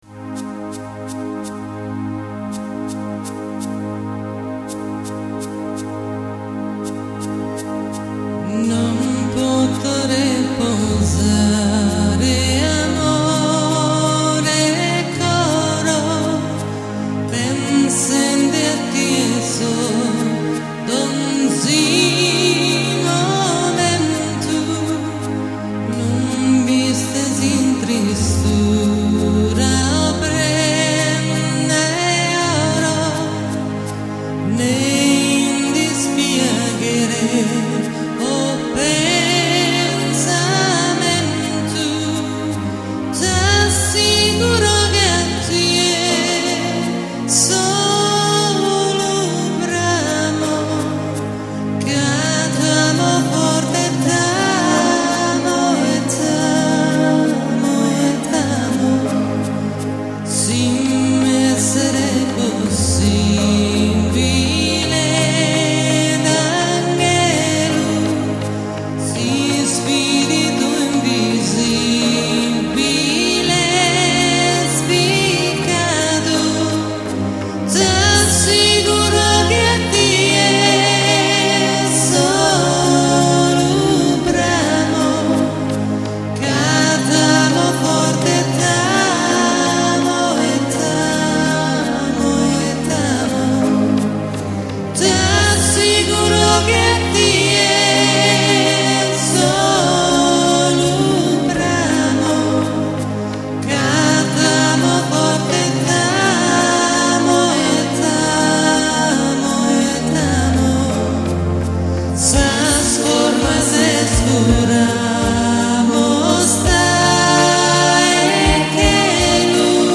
Serata Folkloristica Sarda a Rümlang